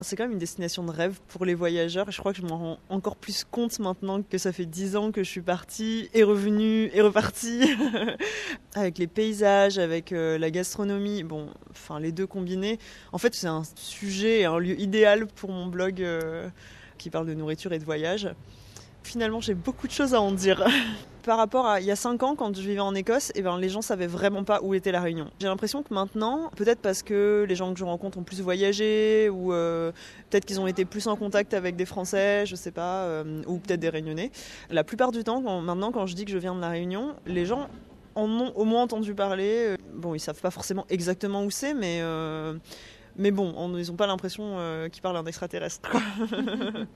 Elle m’a fait parvenir les éléments de l’interview (radio), que j’ai le plaisir de partager avec vous ici.